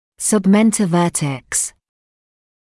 [sʌbˌmentəu’vɜːtəks][сабˌмэнтоу’вё:тэкс]подбородочно-верхушечный